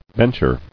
[bench·er]